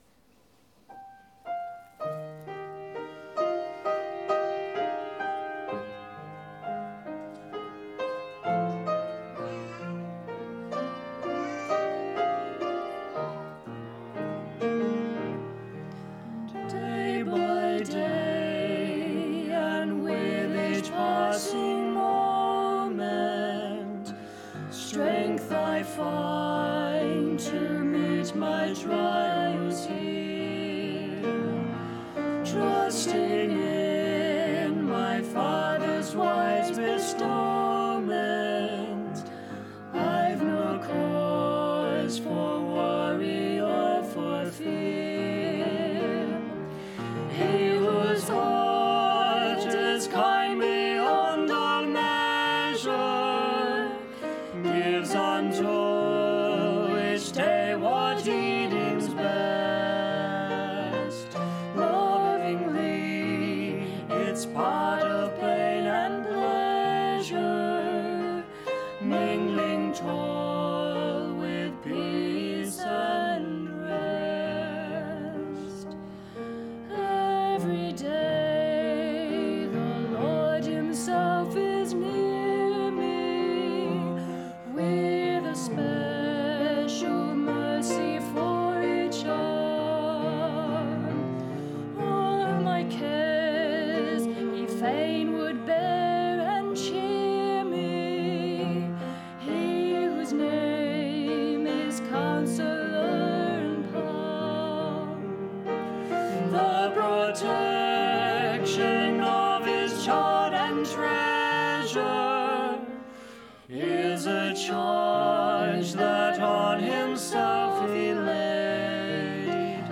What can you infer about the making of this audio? Series: Acts Of The Apostles Service Type: Sunday Evening